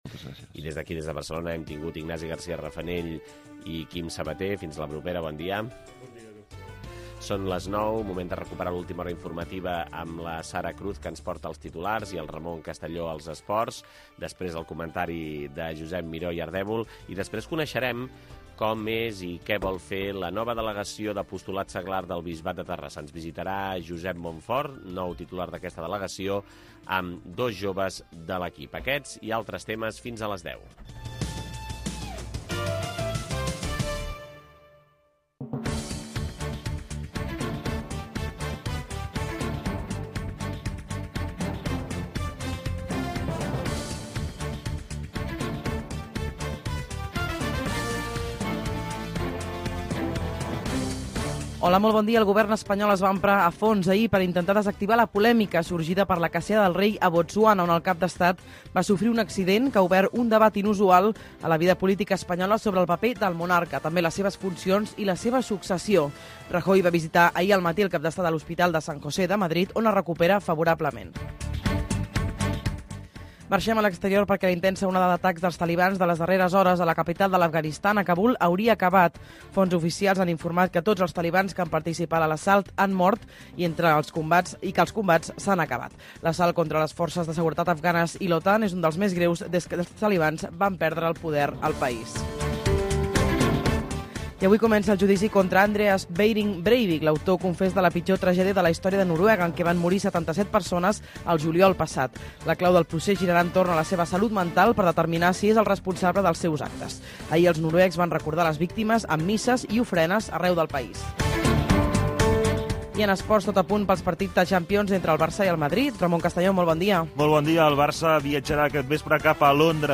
El primer cafè. Informació, actualitat, espais, 2 hores d'un magazin matinal